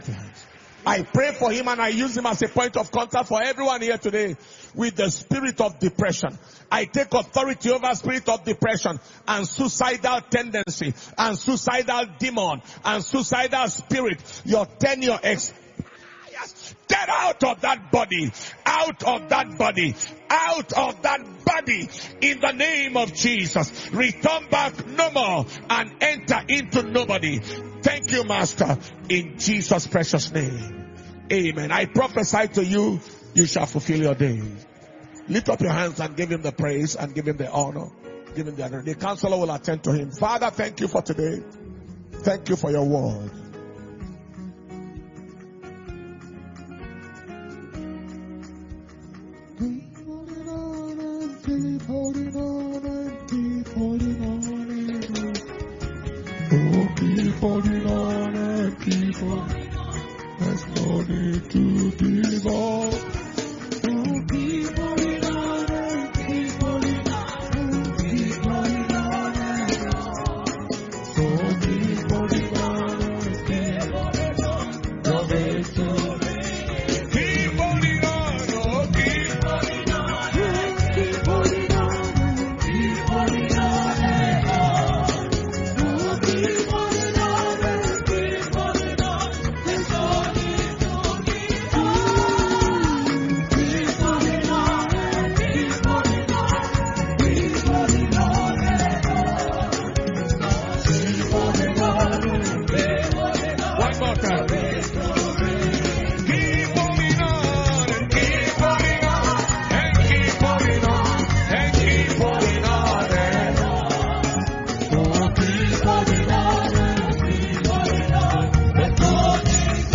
January 2022 Anointing Service – Sunday 9th January 2022